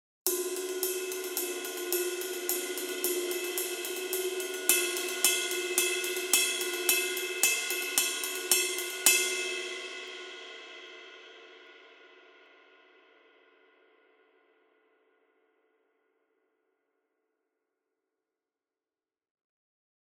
Это обеспечивает невероятно широкий динамический диапазон, землистый теплый звук, четкую атаку и отличную читаемость в миксе.
Masterwork 22 Custom Pointer Ride sample